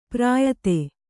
♪ prāyate